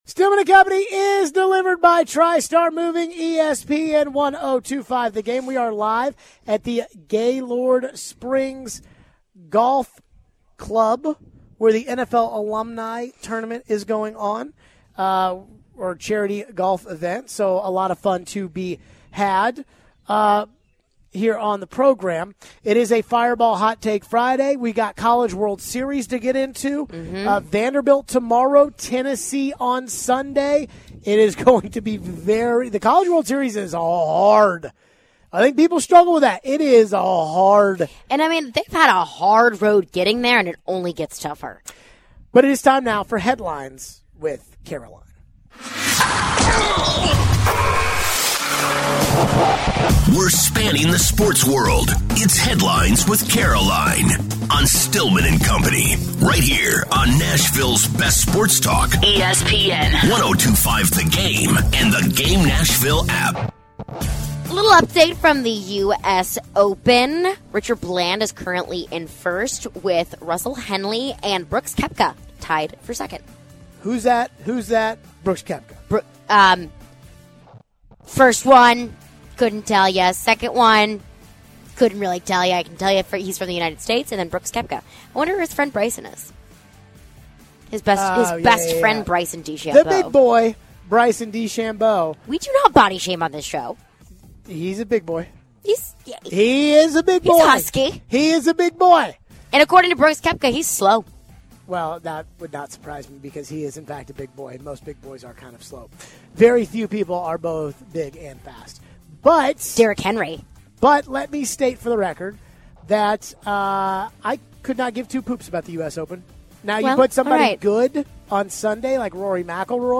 Al Smith, former Oilers LB, joins the show live from the golf course talking Titans and NFL. We take a phone call on the Titans defense and DL.